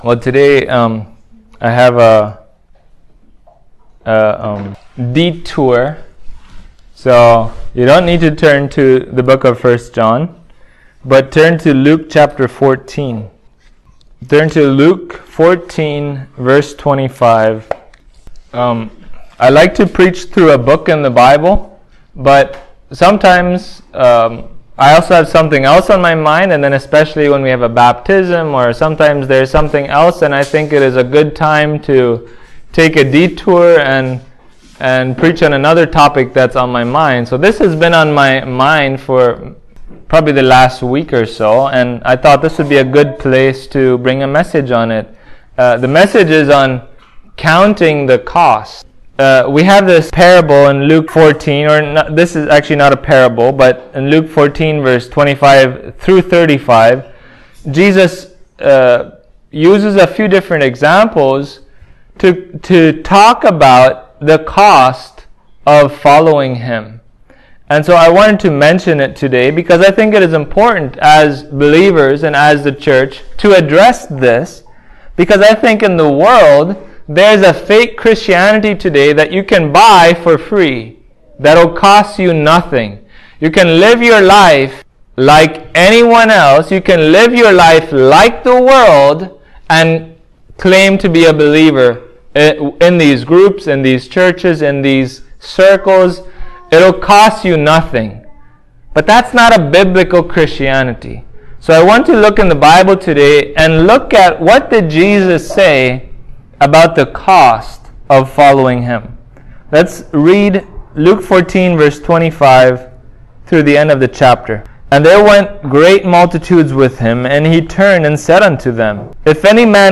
Luke 14:25-35 Service Type: Sunday Morning The path to salvation is not one of “easy-believism”